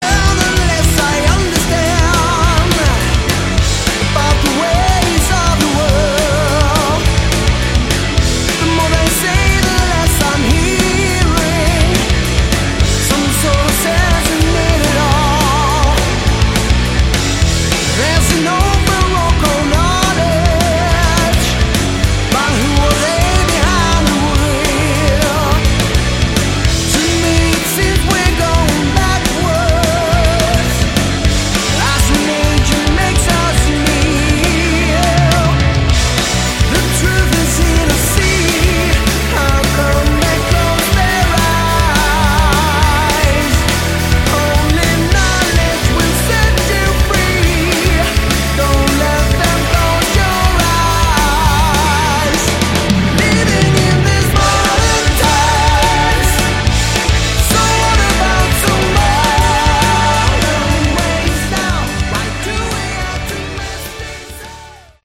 Category: AOR
Drums
Keyboards, Backing Vocals
Guitars, Backing Vocals
Lead Vocals
Bass, Backing Vocals
Very Melodic.